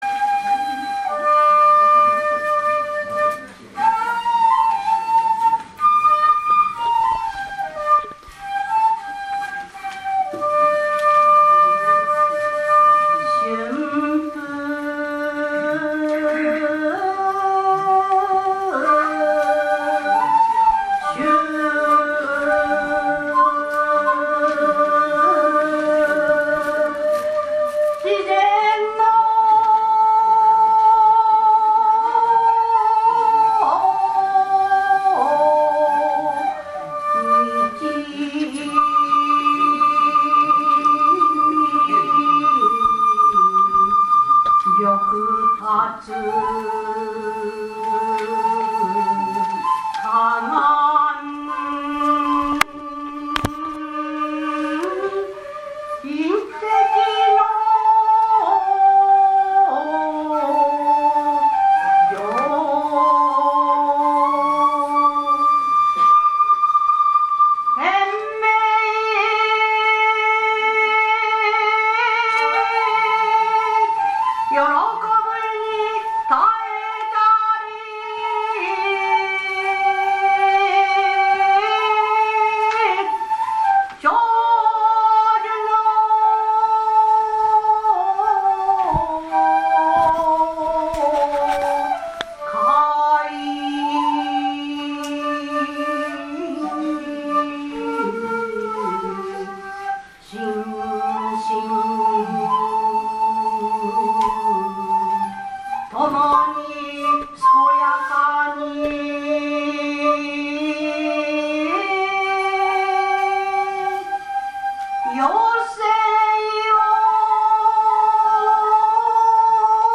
詩吟神風流 第９１回全国大会特集
平成２９年１１月１８日（土） 於 上野精養軒
神号吟詠